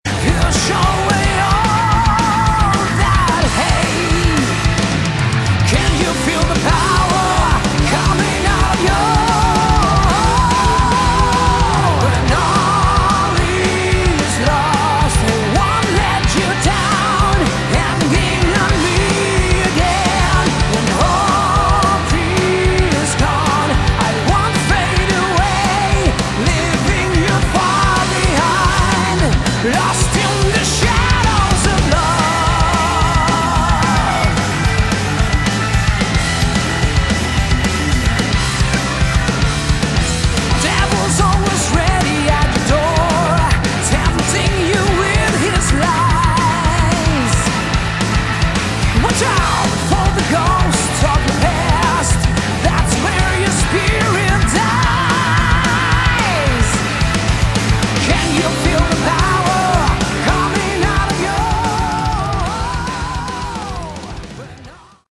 Category: AOR / Melodic Rock
vocals
guitars
keyboards, backing vocals
bass
drums